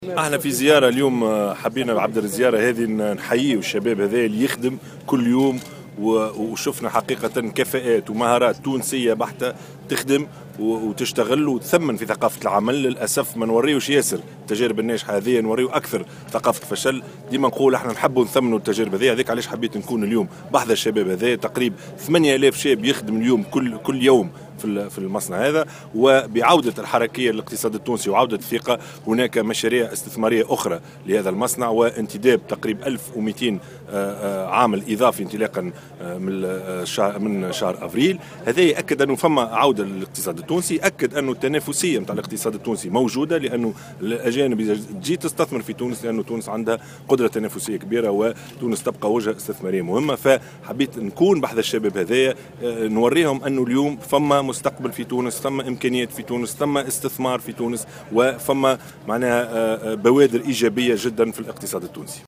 وأضاف رئيس الحكومة، في تصريح لمبعوثة الجوهرة اف ام، أن زيارته اليوم إلى المصنع، تأتي تشجيعا لحوالي 8 ألاف شاب تونسي يشتغل في "ليوني"، وليؤكد لهم أن "تونس فيها مستقبل".وأكّد الشاهد وجود بوادر ايجابية في الاقتصاد التونسي، الذي يتمتع بقدرة تنافسية، مضيفا أن تونس ستظل وجهة استثمارية مهمة.